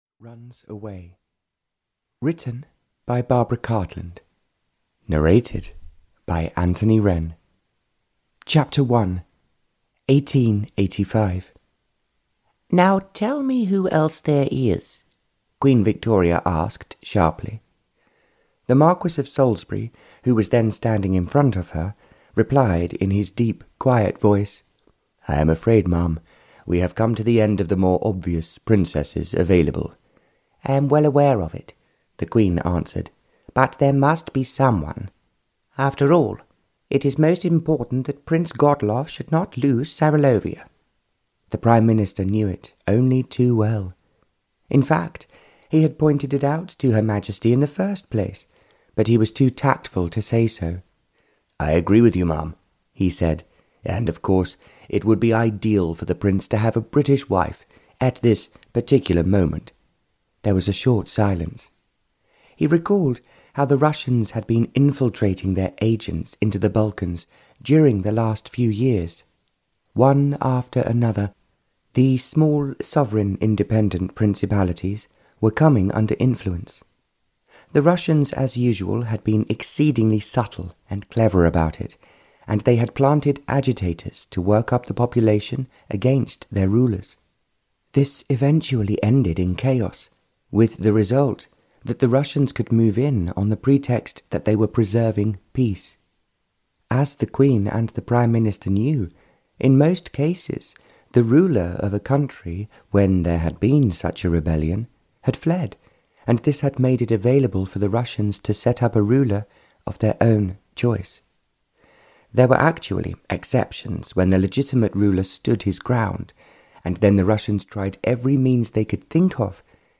Audio knihaA Princess Runs Away (Barbara Cartland's Pink Collection 157) (EN)
Ukázka z knihy